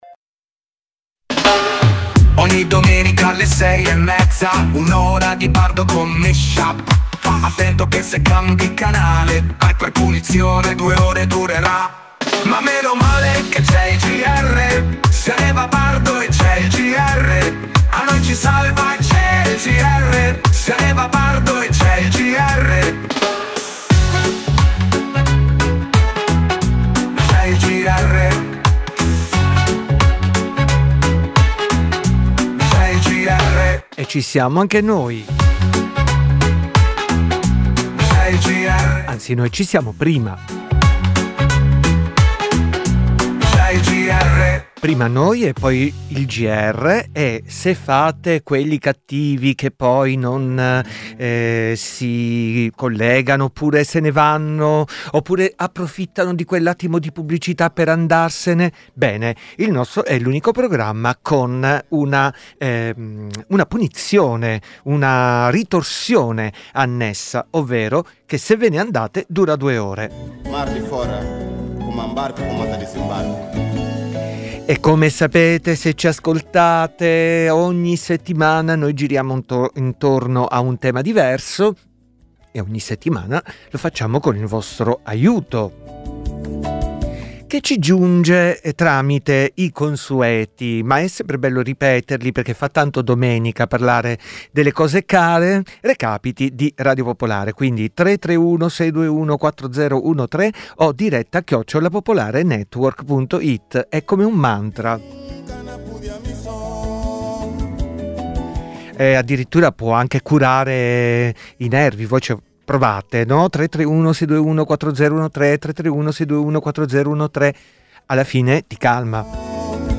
dj set tematico di musica e parole